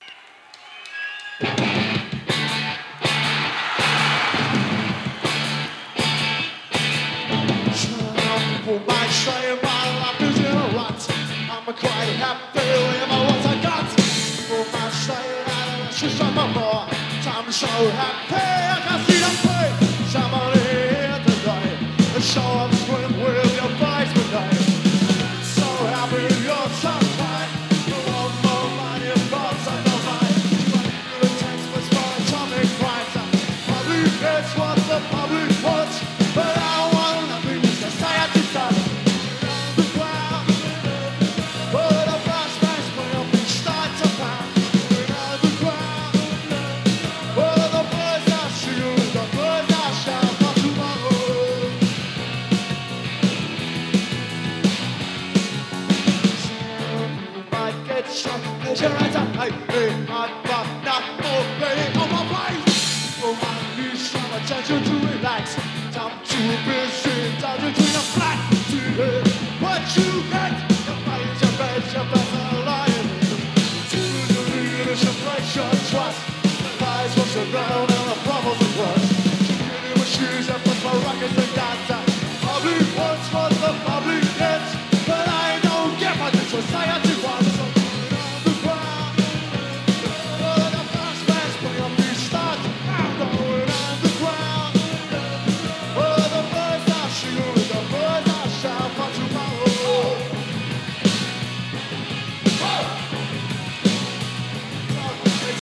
Live CD ネオモッズ
シールド新品 (試聴はLPからの流用)。